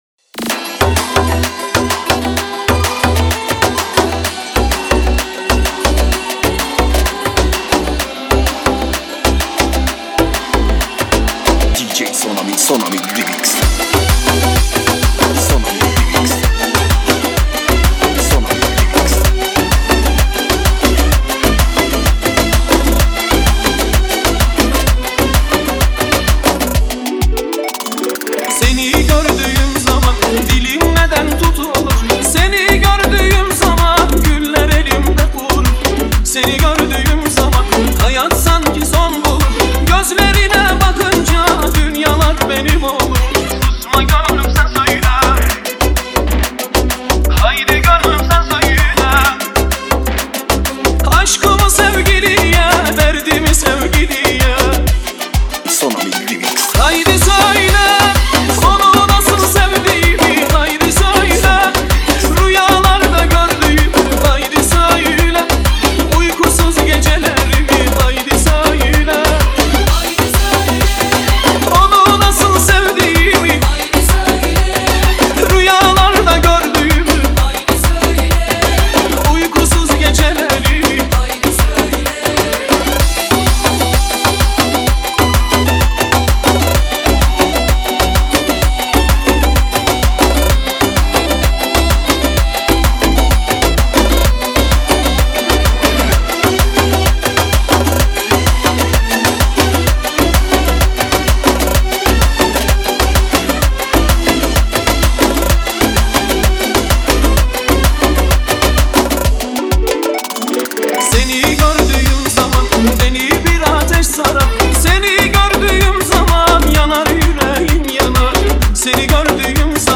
آهنگ شاد ترکی